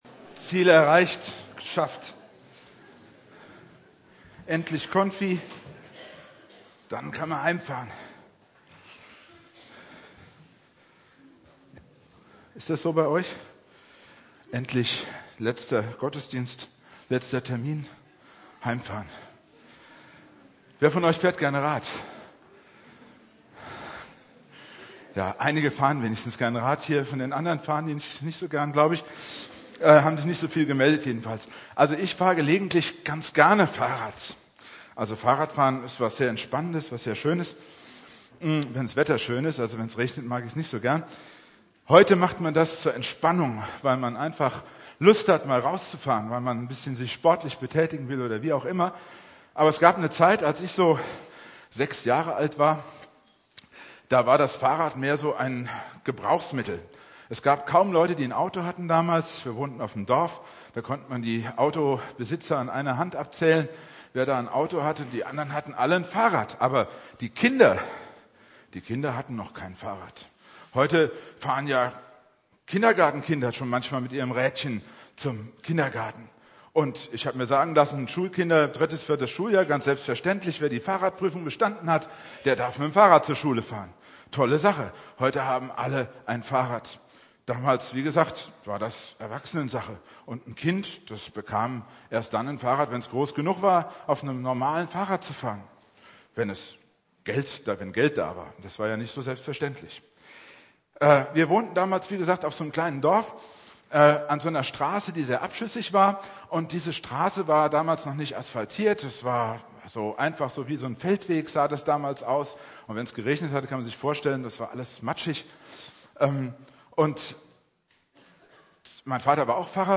Predigt
kommt zum Vater" (Konfirmationsgottesdienst) Bibeltext